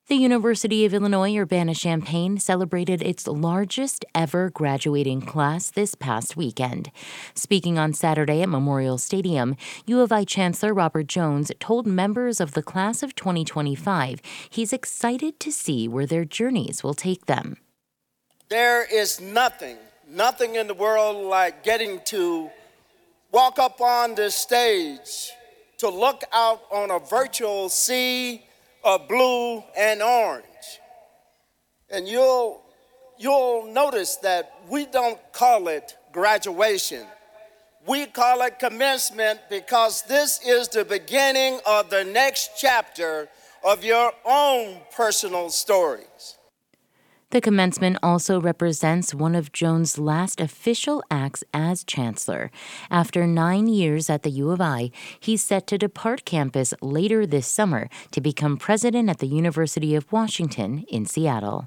University of Illinois Urbana-Champaign Chancellor Robert Jones delivered the commencement address on Saturday, May 17, 2025.
Tens of thousands of students, friends and families filled into Memorial Stadium Saturday morning for the Class of 2025 commencement ceremony.